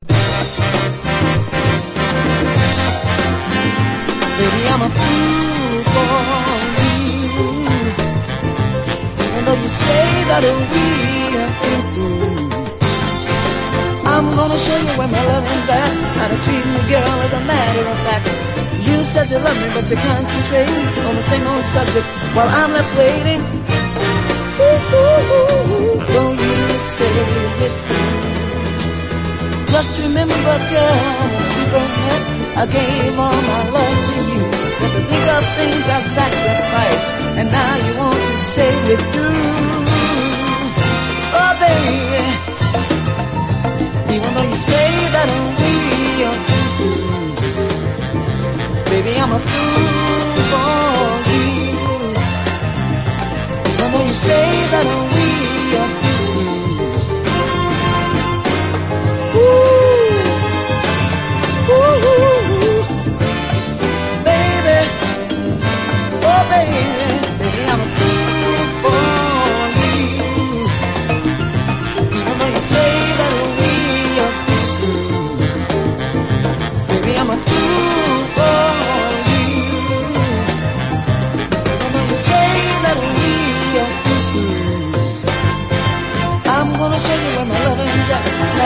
SOUL, 70's～ SOUL, 7INCH
シカゴ・ソウルらしさ炸裂のグレイト・クロスオーヴァー・ソウル！